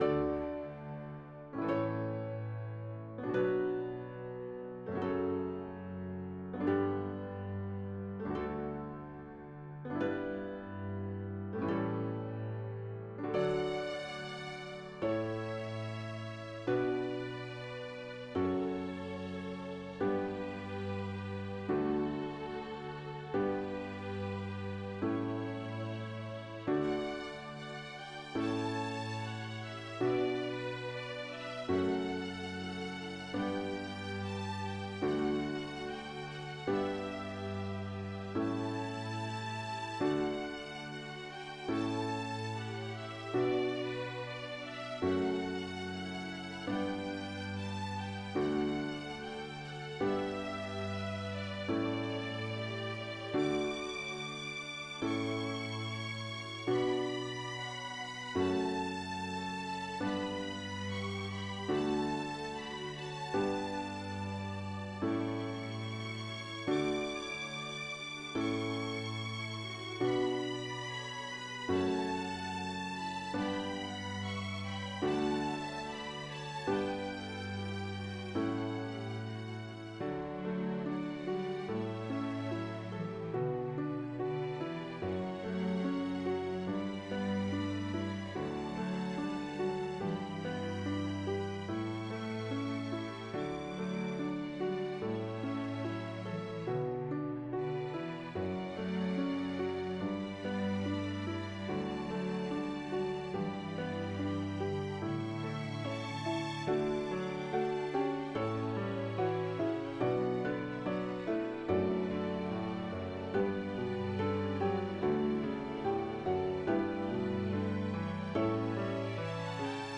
Baroque Period, Christian, Sacred.